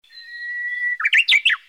hototogisu.mp3